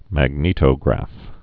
(măg-nētō-grăf)